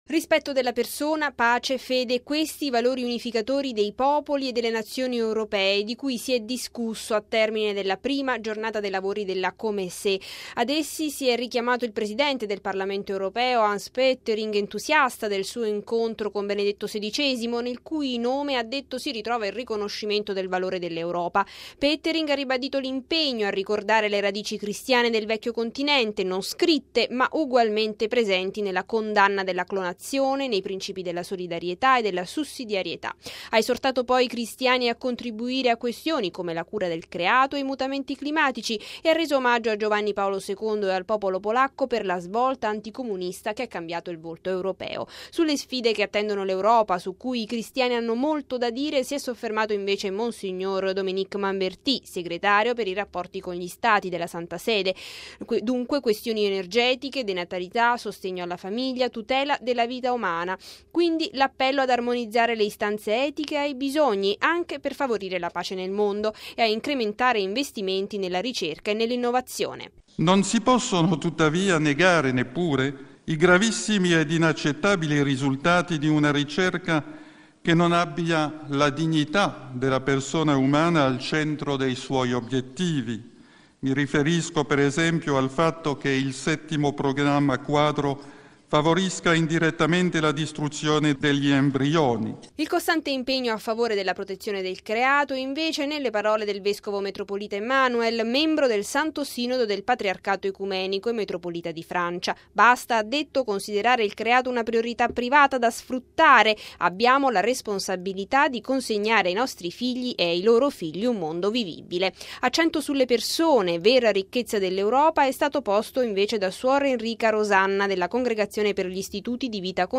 Ma torniamo ai lavori di ieri pomeriggio al Congresso della COMECE.